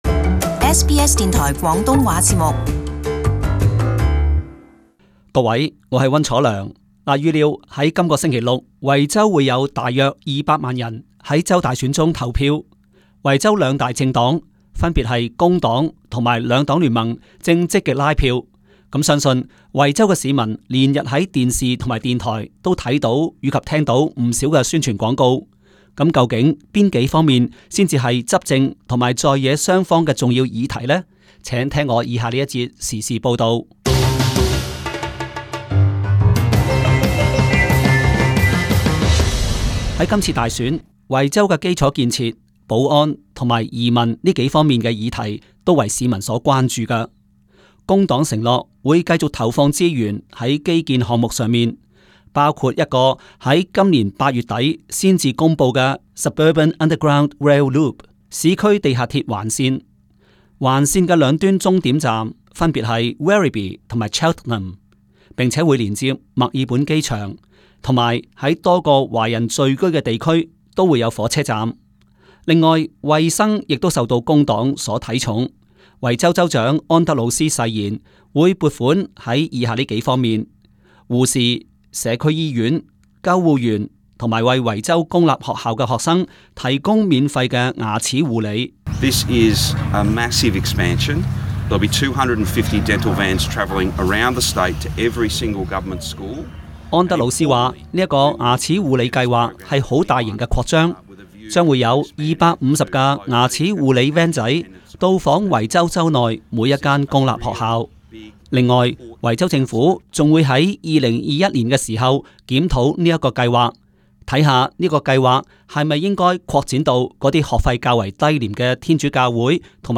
【時事報導】維州大選將至 各政黨推出重點政綱